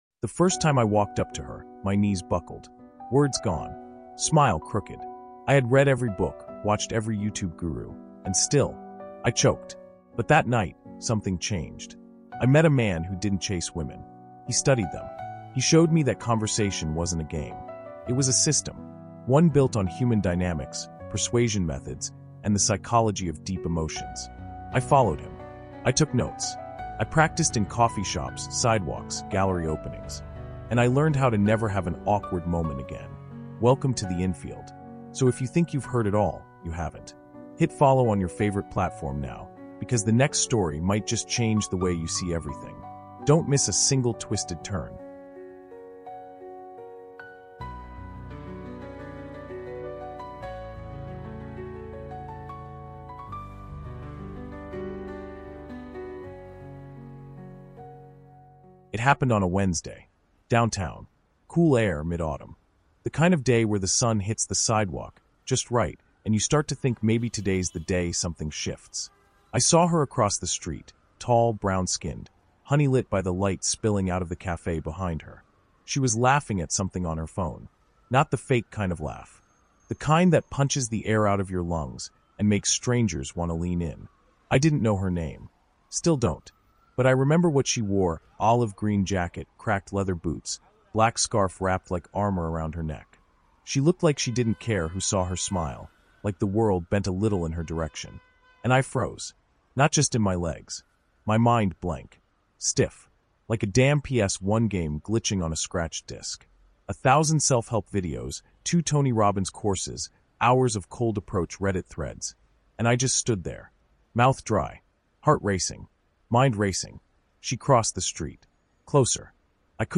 Mastering Infield Conversations with Women: No More Awkward Moments! is a first-person, psychologically immersive audiobook told through the raw voice of a man who transformed from awkward and anxious to emotionally fluent and conversationally sovereign.